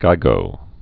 (gīgō, gē-)